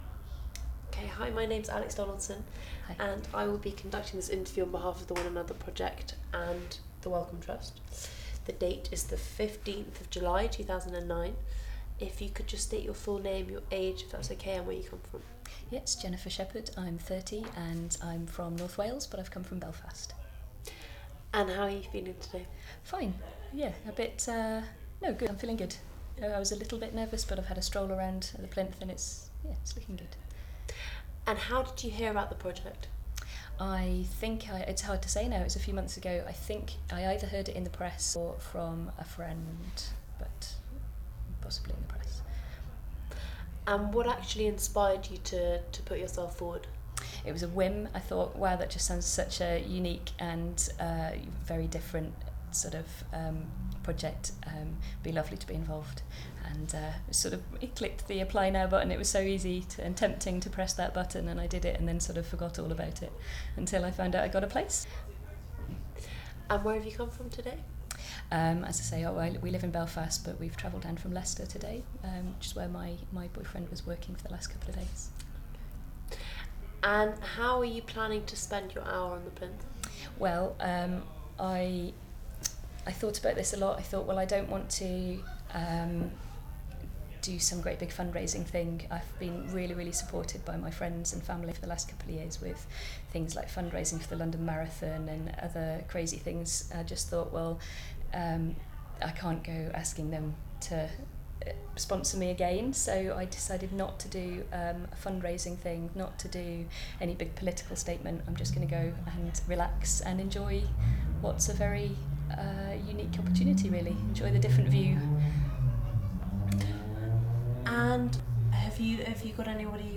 Audio file duration: 00:09:03 Format of original recording: wav 44.1 khz 16 bit MicportPro.